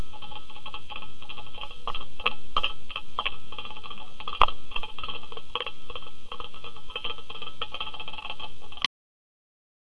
Что-то едят